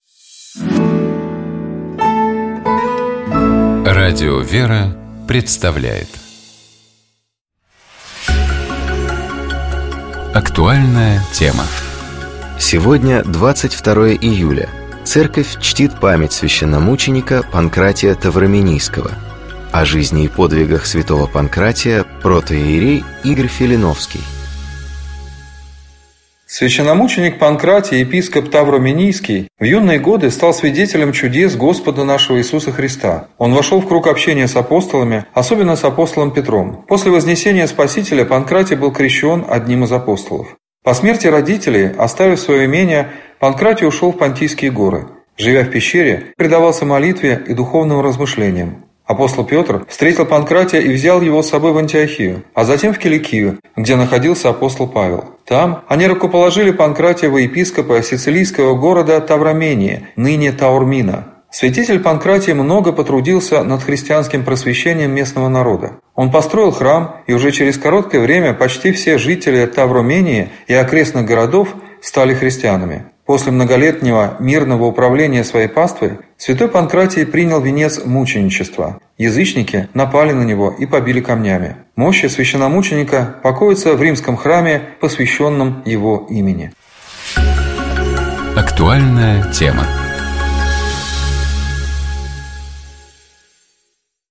Библейские чтения › ‹ Книга пророка Исайи, Глава 66, стихи 10-24 › ‹ Читает и комментирует священник